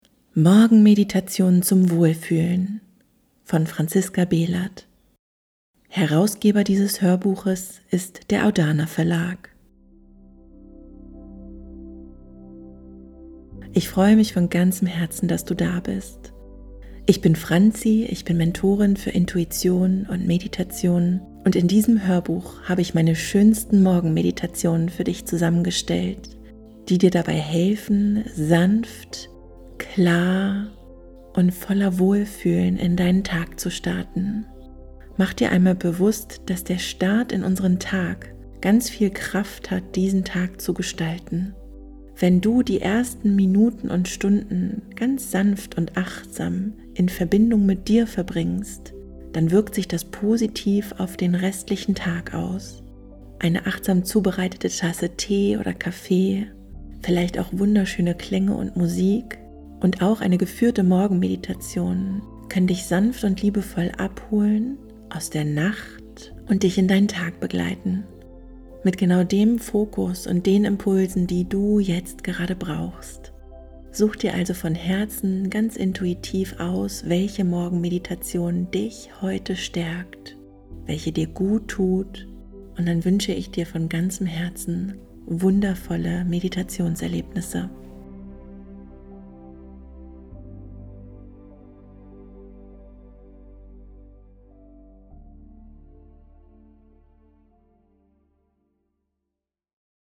Dieses Album begleitet dich dabei, ruhig, klar und mit einem guten Gefühl in den Tag zu starten. Die geführten Morgenmeditationen helfen dir, nach dem Aufwachen erst einmal bei dir selbst anzukommen, tief durchzuatmen und dich innerlich zu sammeln, bevor der Alltag beginnt.